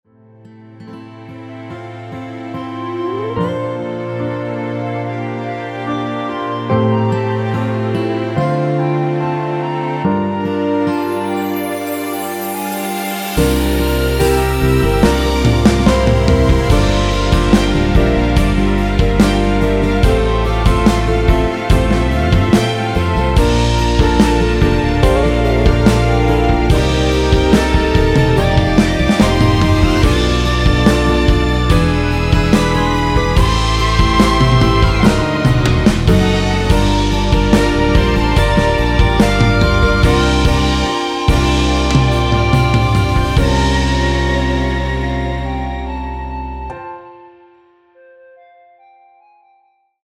1절 + 후렴 편곡 MR 입니다.
노래가 바로 시작 하는 곡이라 전주 만들어 놓았으며
원키에서(+5)올린 멜로디 포함된 1절후 후렴으로 진행되게 편곡한 MR 입니다.(미리듣기및 가사 참조)
앞부분30초, 뒷부분30초씩 편집해서 올려 드리고 있습니다.
중간에 음이 끈어지고 다시 나오는 이유는